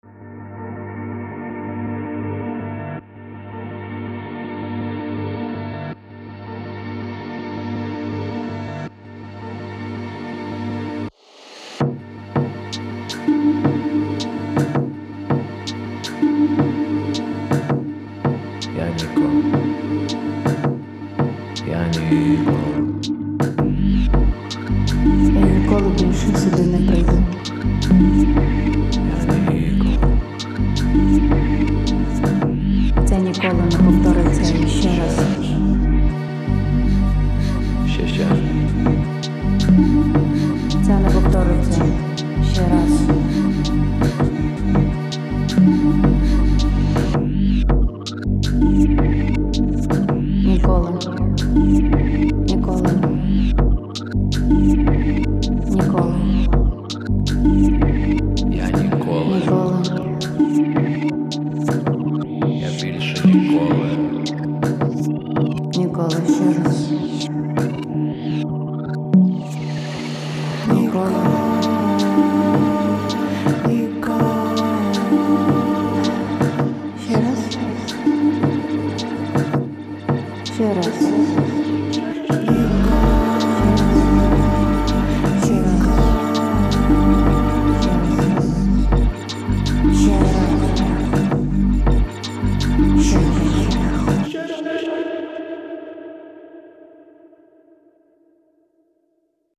• Жанр: Soul